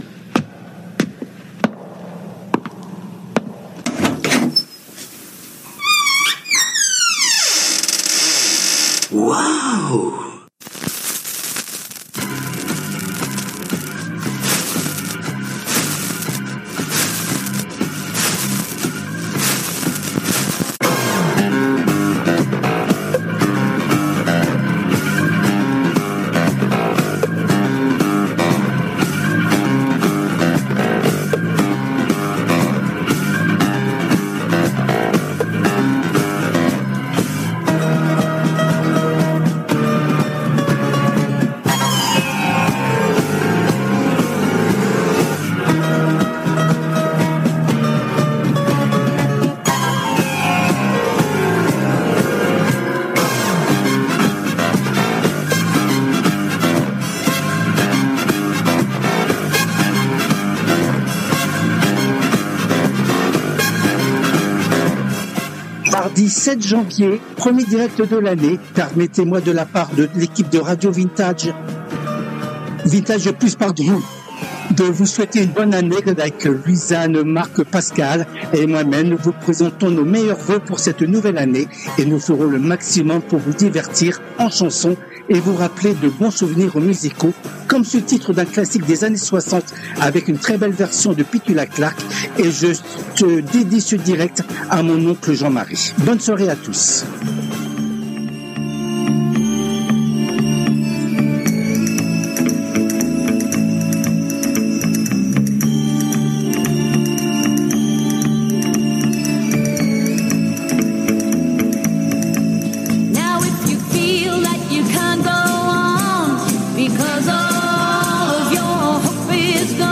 Les Tubes connus ou oubliés des 60's, 70's et 80's